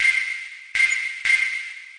这是个由14个循环组成的简单包，速度为120 bpm，适合于工业和类似的音乐。这些循环的样本都是用Sonic Charge microTonic drumsynth制作的，没有经过处理。